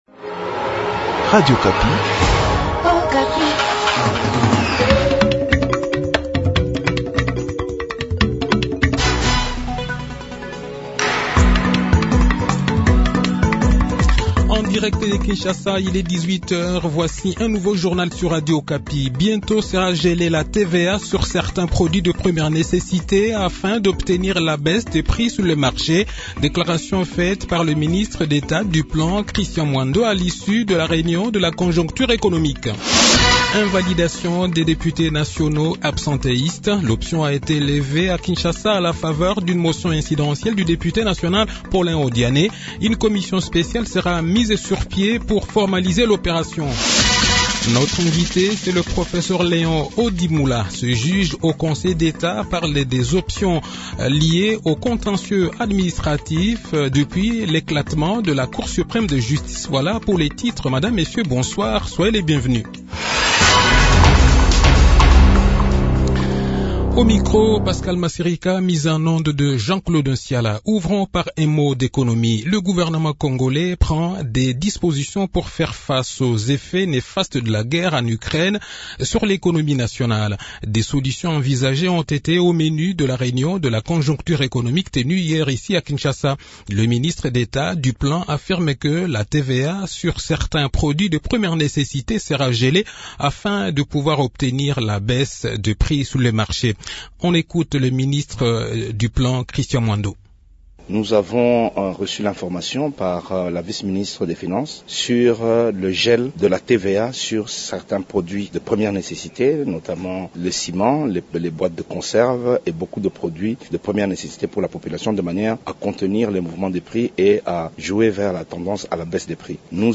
Le journal de 18 h, 21 Avril 2022